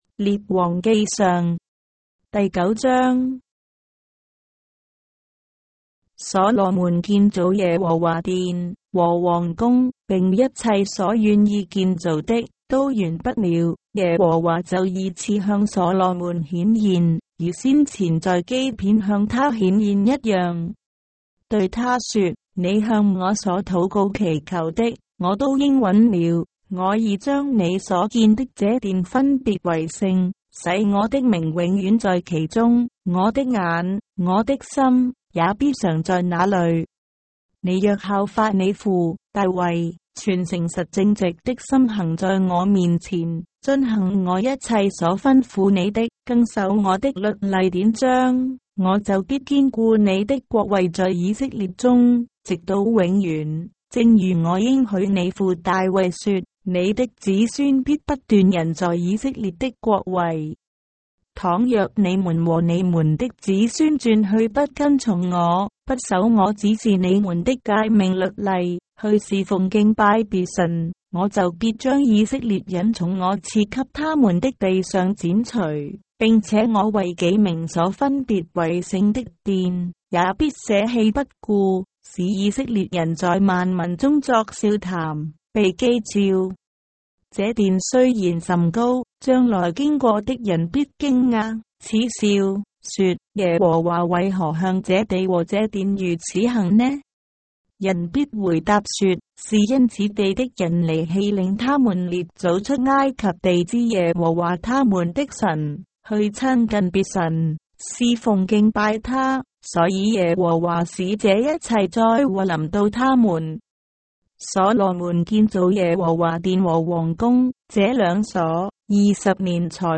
章的聖經在中國的語言，音頻旁白- 1 Kings, chapter 9 of the Holy Bible in Traditional Chinese